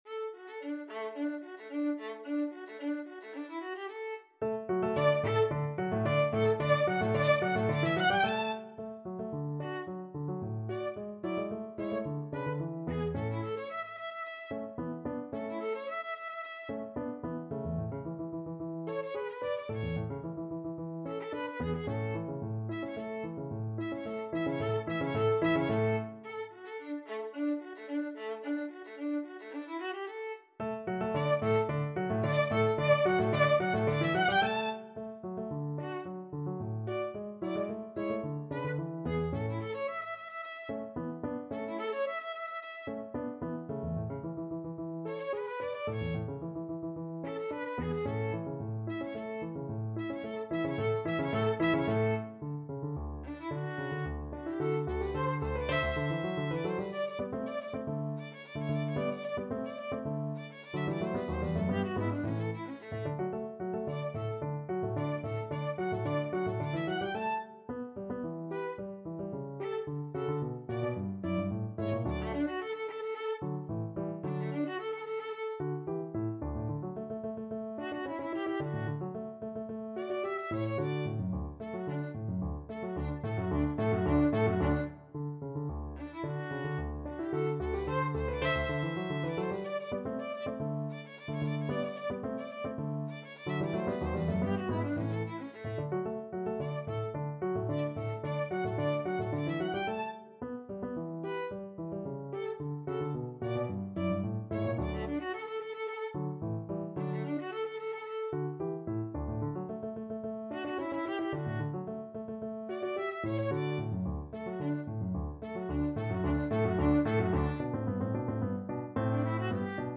Allegro =110 (View more music marked Allegro)
4/4 (View more 4/4 Music)
A4-A6
Instrument:
Violin  (View more Intermediate Violin Music)
Classical (View more Classical Violin Music)